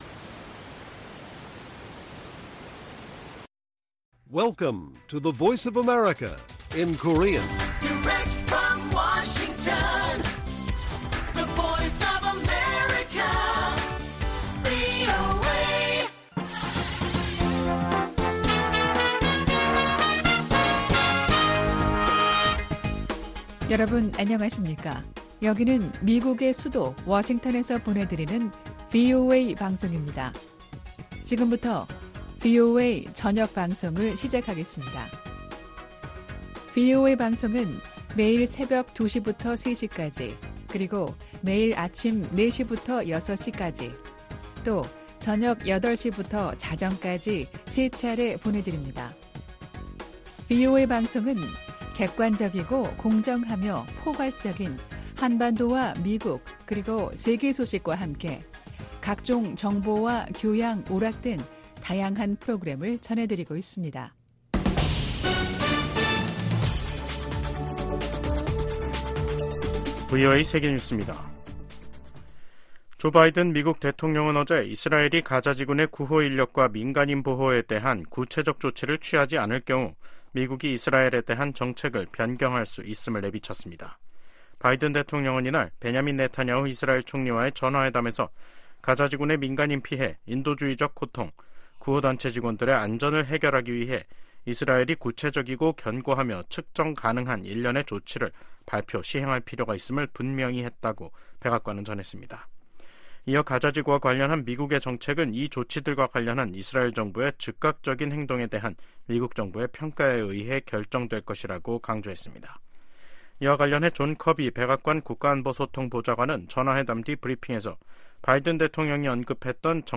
VOA 한국어 간판 뉴스 프로그램 '뉴스 투데이', 2024년 4월 5일 1부 방송입니다. 유엔 인권이사회가 올해 20번째로 북한의 심각한 인권 상황을 규탄하는 북한인권결의안을 채택했습니다. 한국과 중국, 일본이 4년여 간 중단됐던 3국 정상회의를 다음달 중 서울에서 개최하는 방안을 협의 중입니다. 유엔 안보리에서 미한일 3국 대표가 북한의 악의적인 사이버 공격에 대한 우려를 표명했습니다.